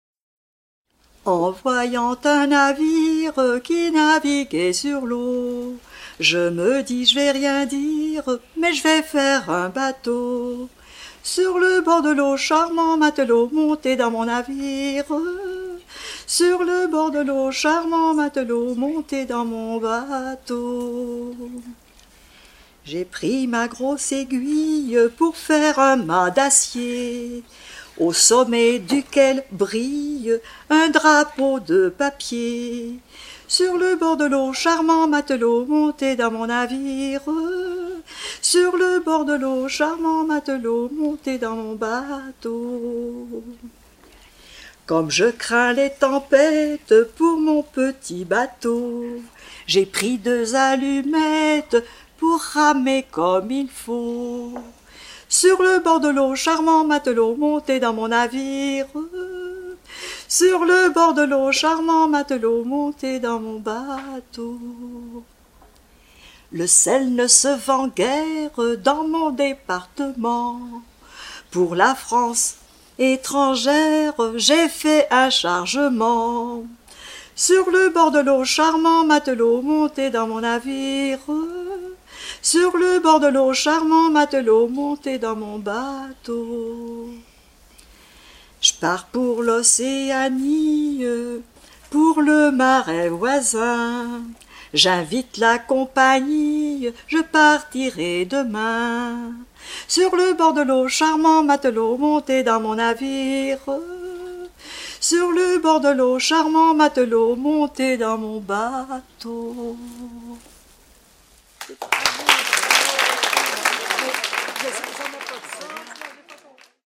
Enfantines diverses
Genre strophique